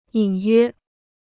隐约 (yǐn yuē): vague; faint.